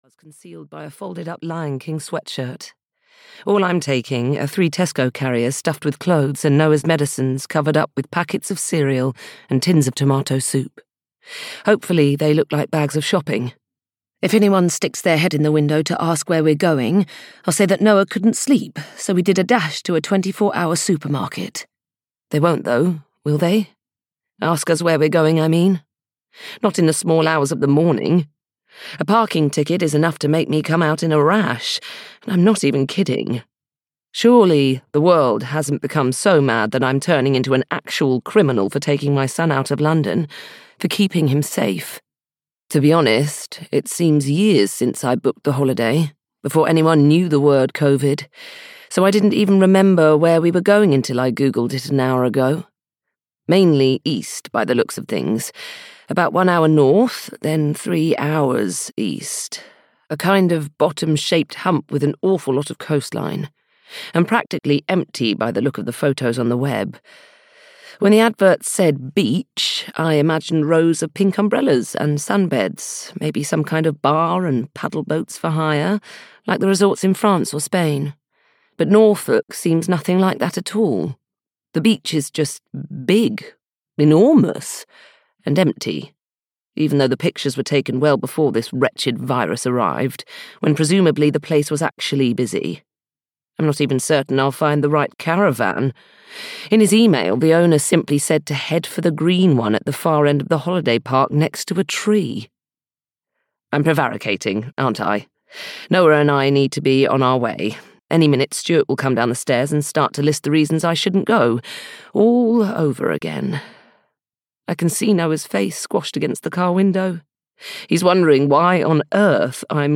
Letters to a Stranger (EN) audiokniha
Ukázka z knihy